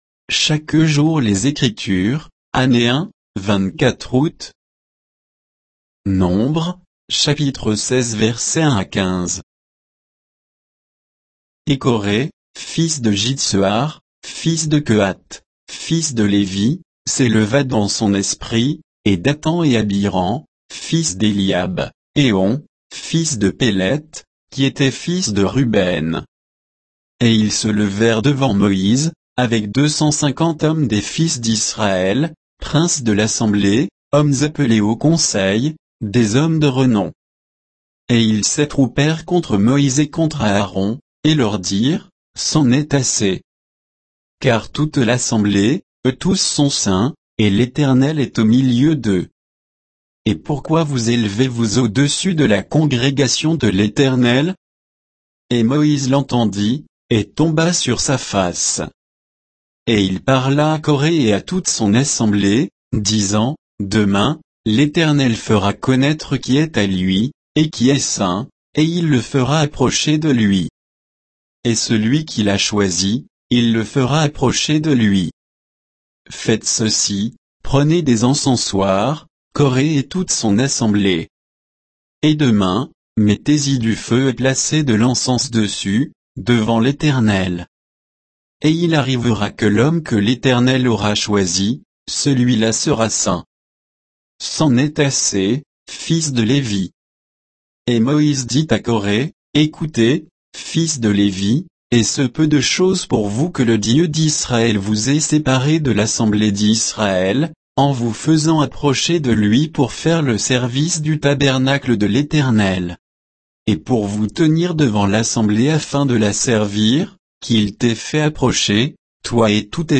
Méditation quoditienne de Chaque jour les Écritures sur Nombres 16, 1 à 15